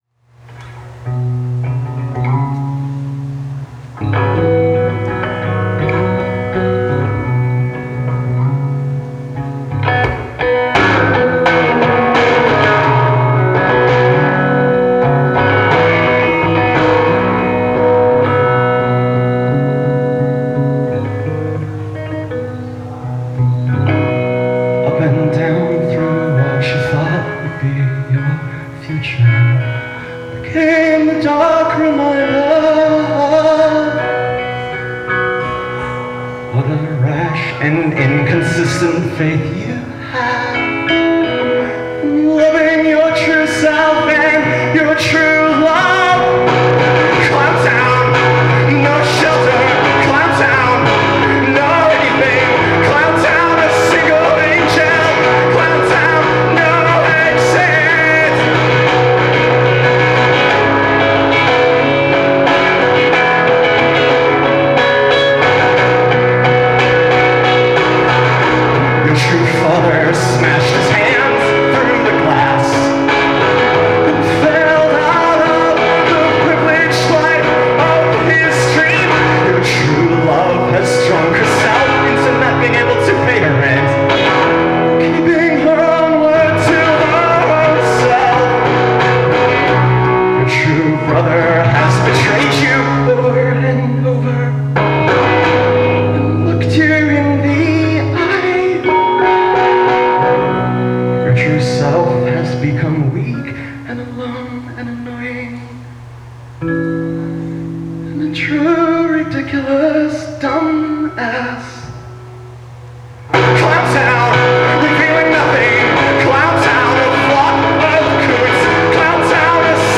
Acoustic ….mp3